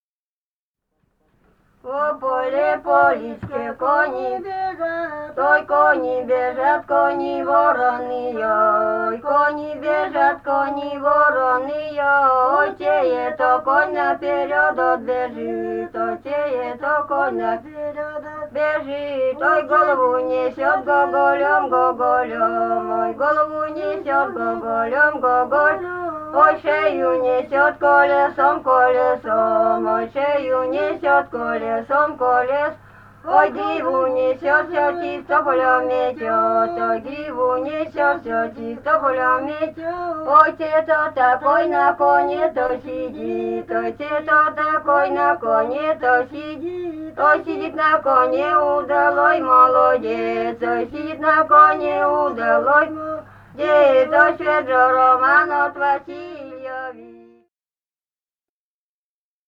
Этномузыкологические исследования и полевые материалы
Пермский край, д. Кокуй Кунгурского района, 1968 г. И1080-09